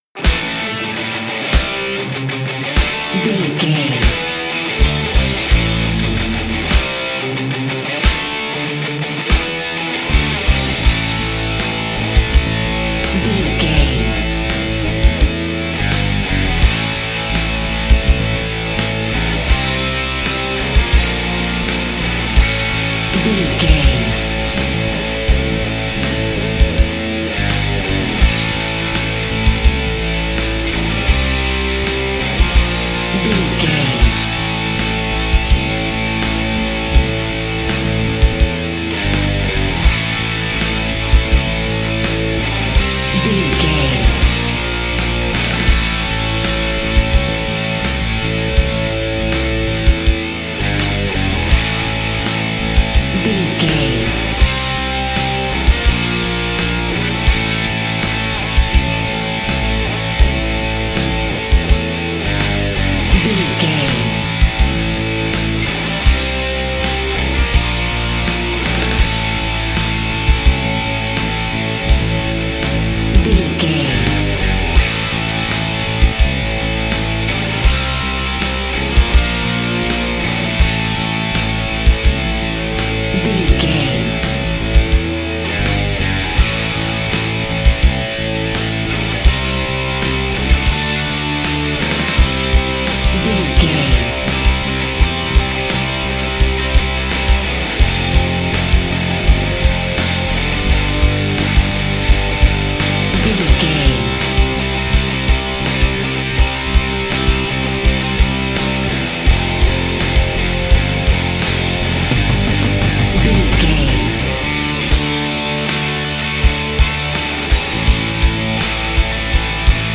Mixolydian
electric guitar
hard rock
lead guitar
bass
drums
aggressive
energetic
intense
nu metal
alternative metal